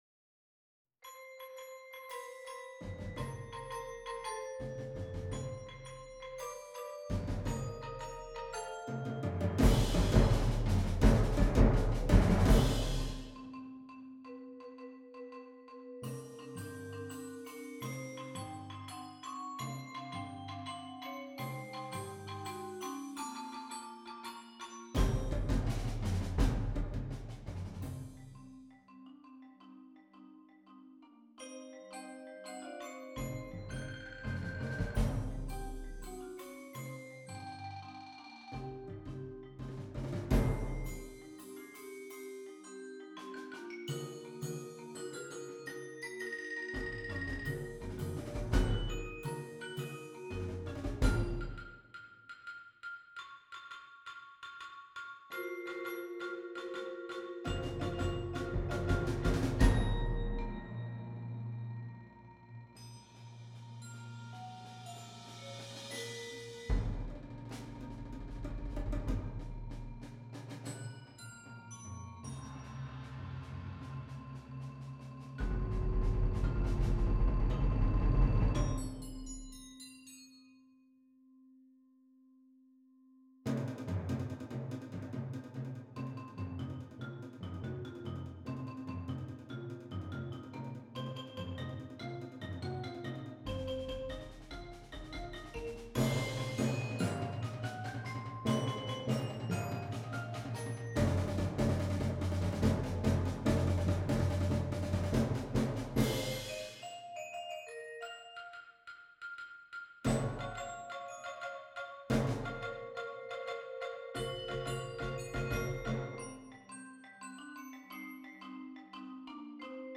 Gattung: Für Percussion Ensemble
Besetzung: Instrumentalnoten für Schlagzeug/Percussion